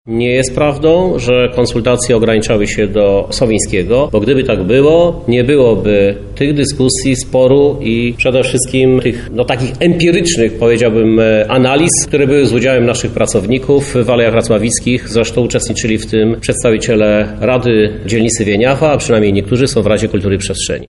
Krzysztof Żuk, prezydent miasta odpiera zarzuty.